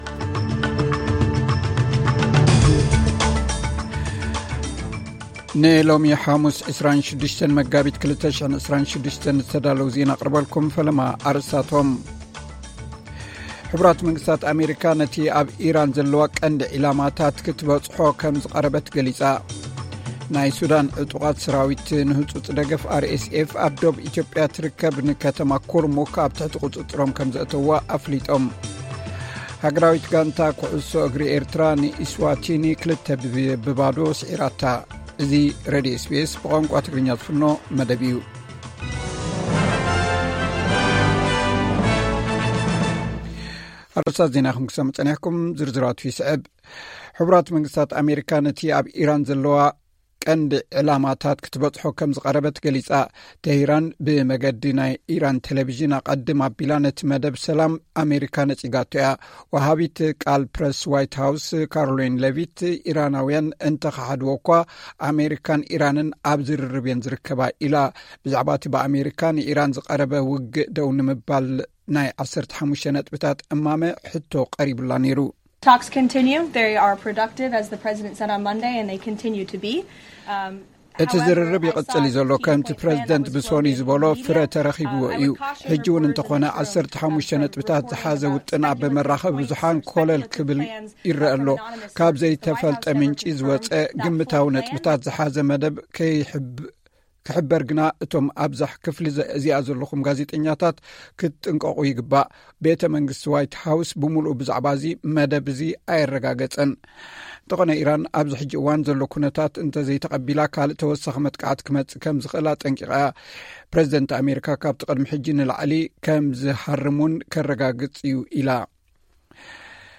ዕለታዊ ዜና SBS ትግርኛ (26 መጋቢት 2026)